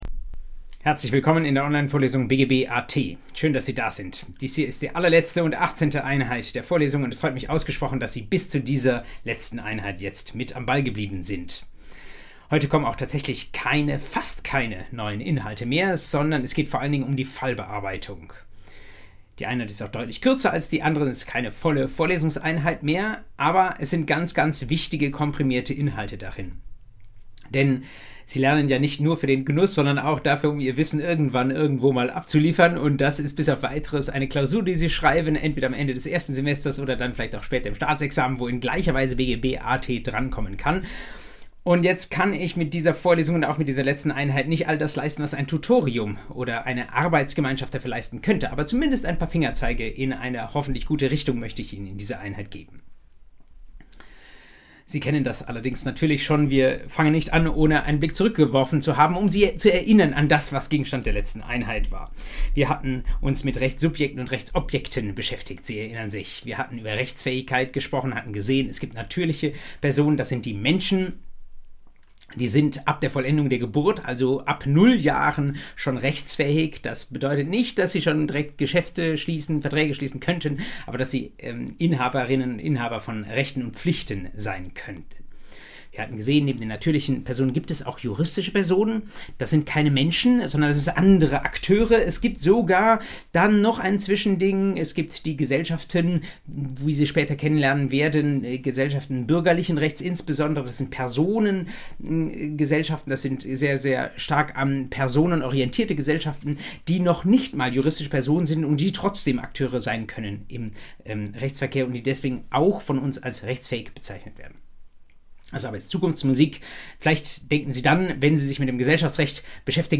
BGB AT Folge 18: Fallbearbeitung ~ Vorlesung BGB AT Podcast